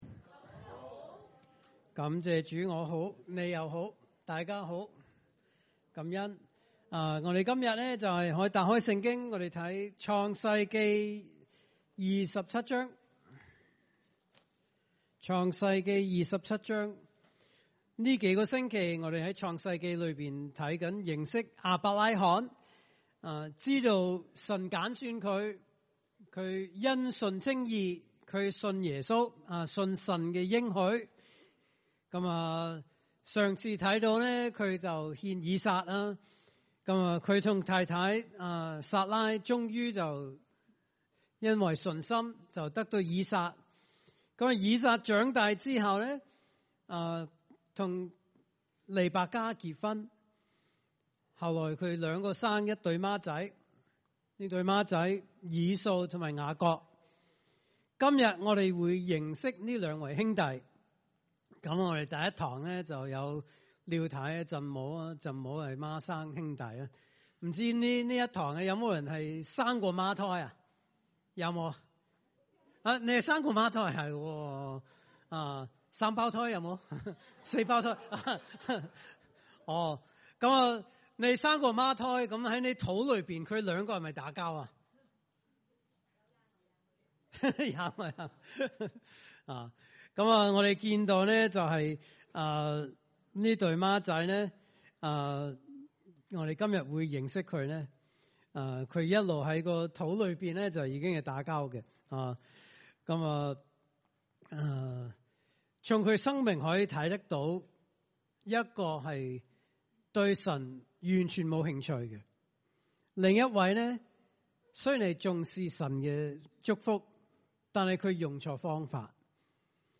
來自講道系列 "解經式講道"